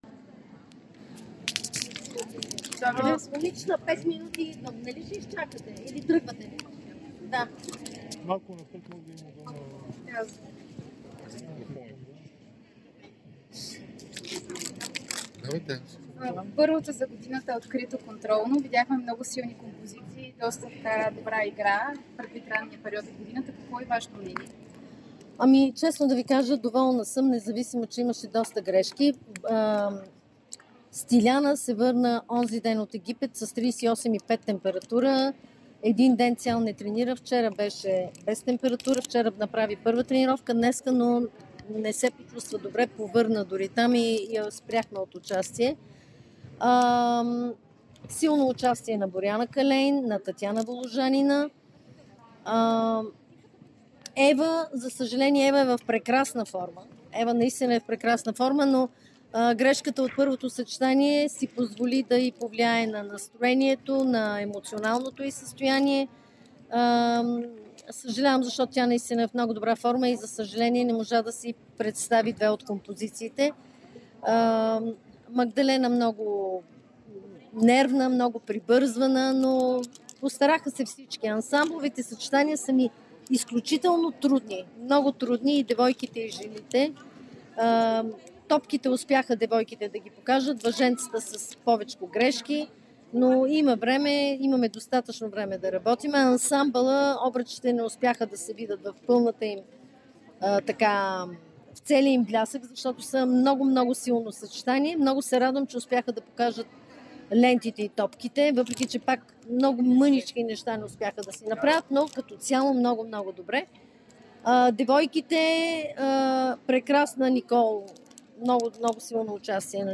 Президентът на БФХГ Илиана Раева говори пред медиите след контролното на националните отбори на България за жени и девойки в тренировъчната зала към „Арена София“.